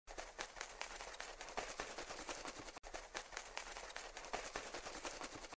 Звуки мотылька
На этой странице собраны уникальные звуки мотыльков: от легкого шелеста крыльев до едва уловимого трепета в ночи.
Звук крыльев бабочки